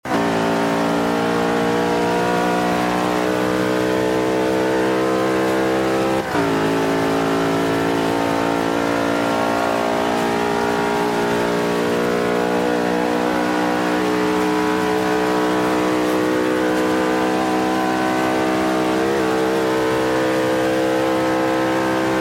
Index of /server/sound/vehicles/lwcars/merc_slk55
fourth_cruise.wav